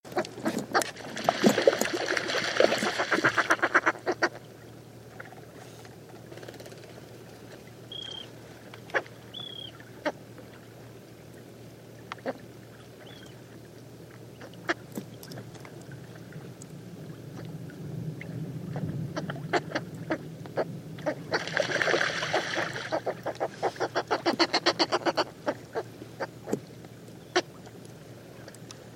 Northern fulmar
Cackling and grunting calls are emitted at the nesting site; in flight fulmars are usually silent.
NP_LYD_HAVHEST_SNI.mp3